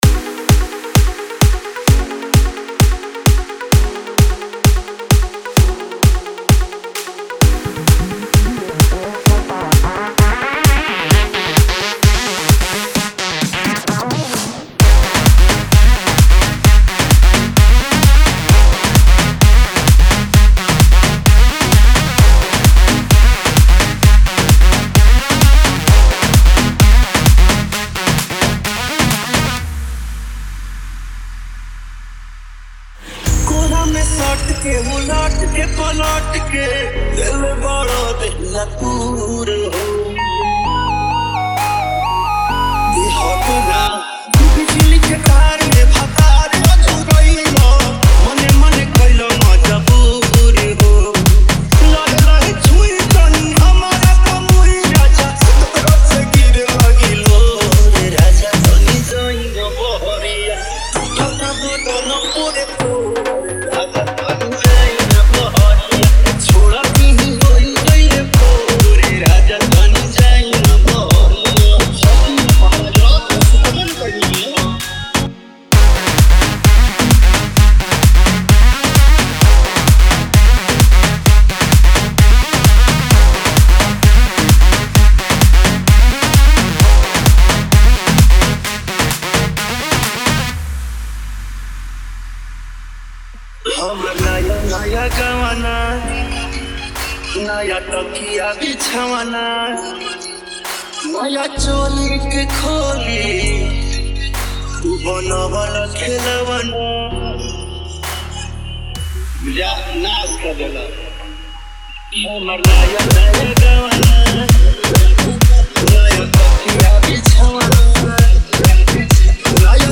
Bhojpuri DJ Remix Songs